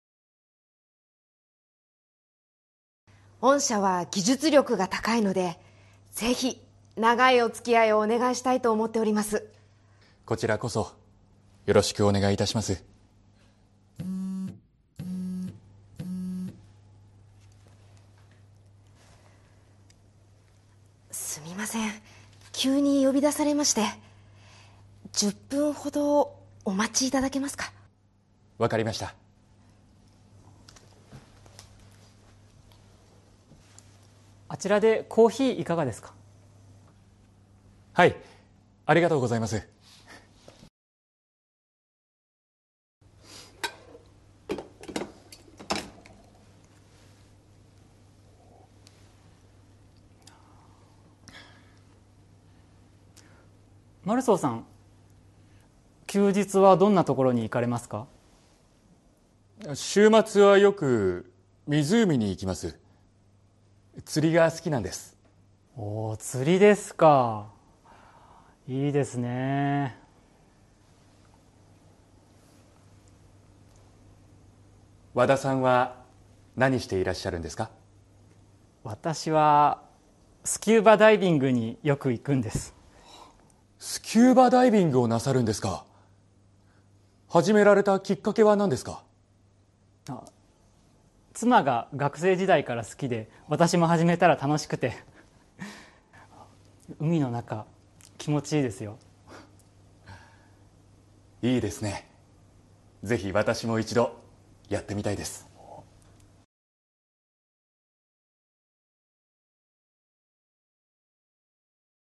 Role-play Setup
But based on their tone of voice, we can tell that they may not actually be interested.